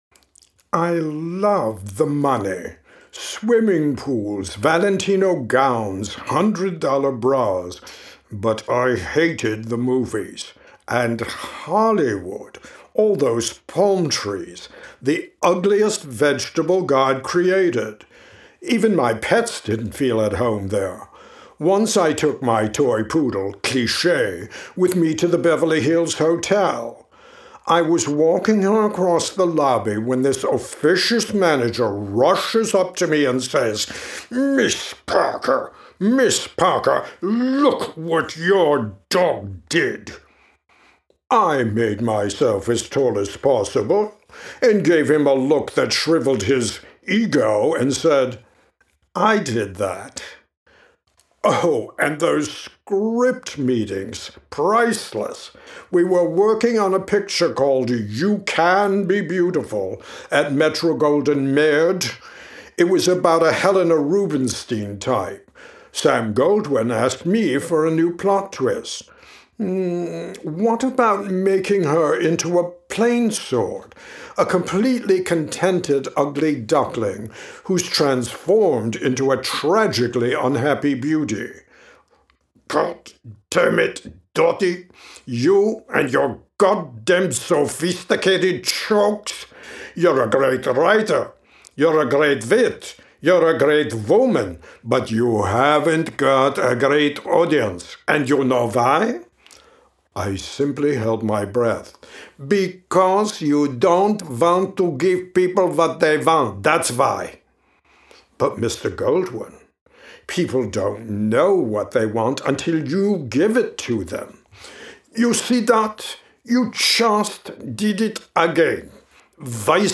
This hilarious monologue is from the Dorothy Parker musical, You Might as Well Live, excerpted from audiobook: The Wrong Side of the Room
The 3-minute Dorothy Parker monologue, narrated by me, is from my musical about Mrs. Parker, You Might as Well Live.